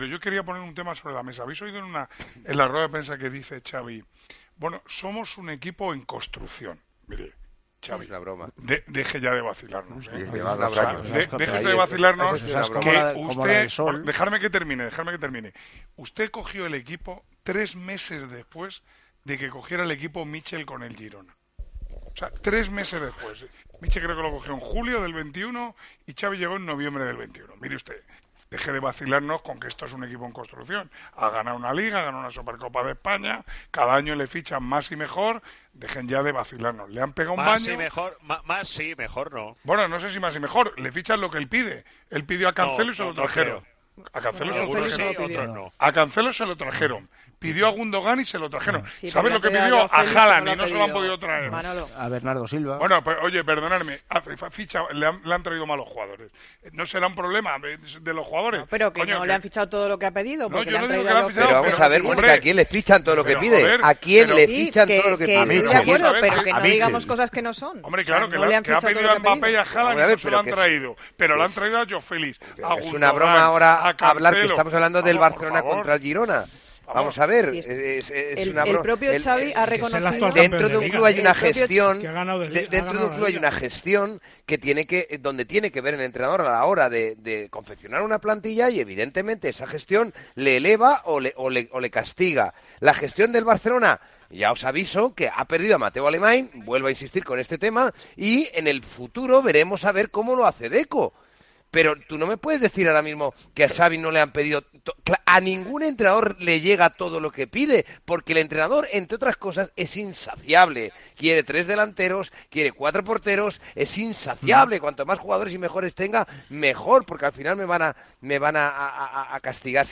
En el Tertulión de los domingos de Tiempo de Juego, el equipo de comentaristas analizó la derrota de los de Xavi y las palabras de este en rueda de prensa tras el partido.